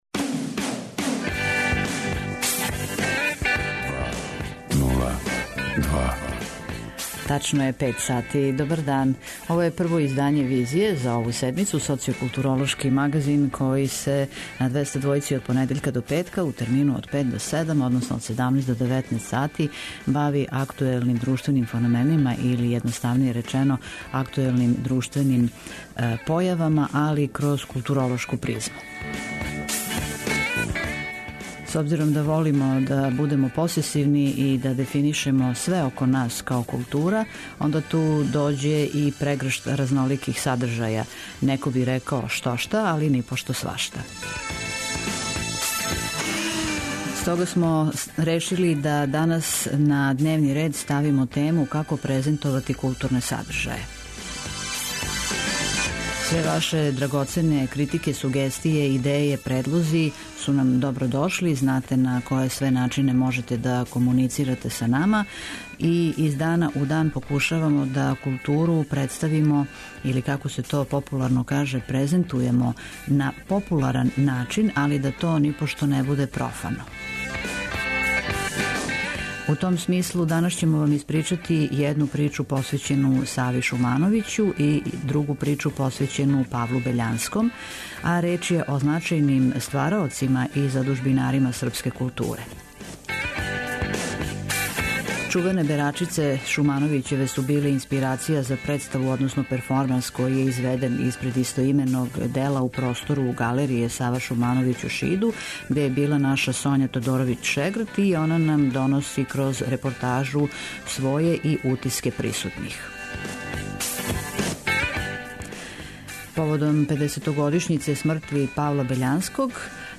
Данашња „Визија" подсећа на значајне ствараоце и задужбинаре српске културе: Саву Шумановића и Павла Бељанског. Шумановићеве „Берачице" су биле инспирација за преставу - перформанс, изведен испред истоименог дела у простору Галерије „Сава Шумановић" у Шиду, одакле стиже и репортажа снимљена у галерији.